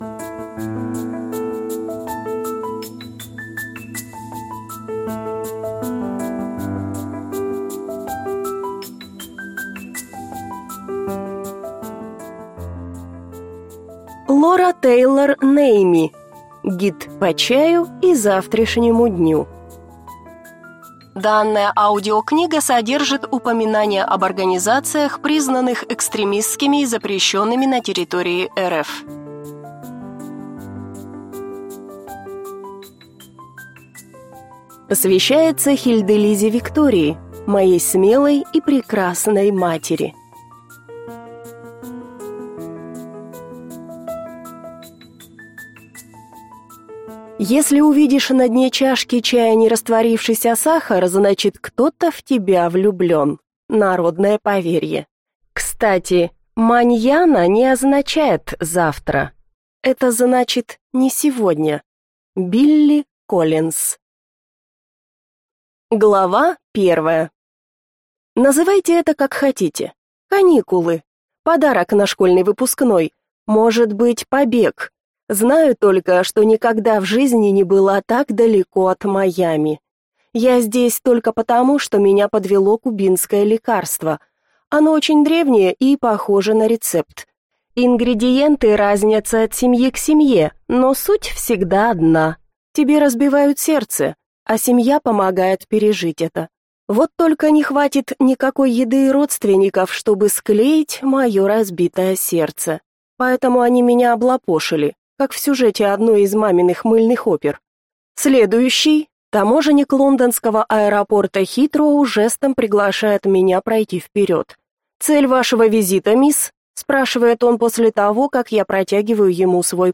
Аудиокнига Гид по чаю и завтрашнему дню | Библиотека аудиокниг